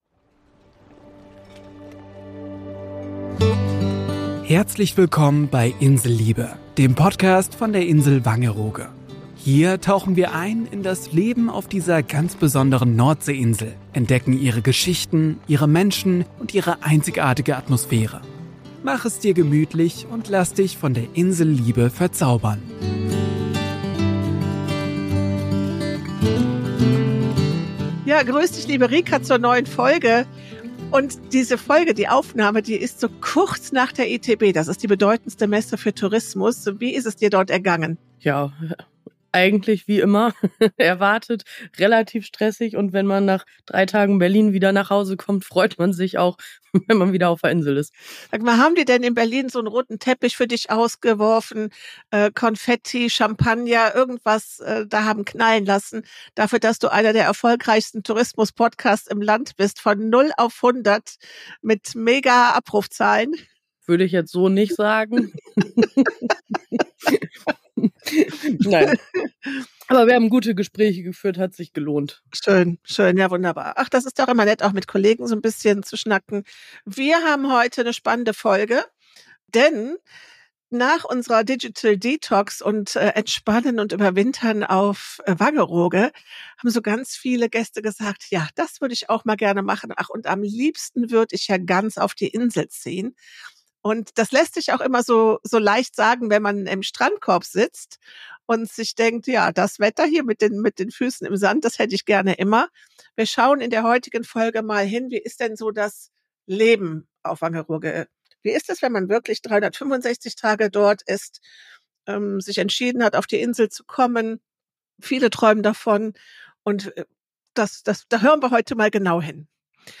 Aber hören Sie doch selbst rein bei dem Talk mit Meeresrauschen!